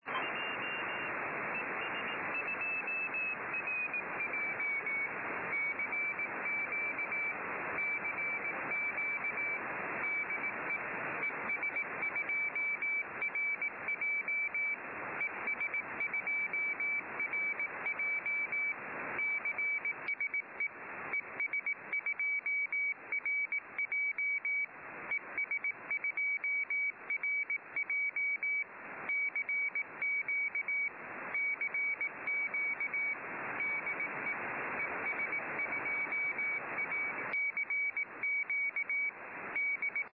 Pierwsza łączność SP- ES w paśmie 10 GHz,  rekordowy ODX